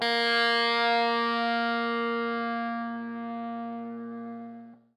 SPOOKY    AK.wav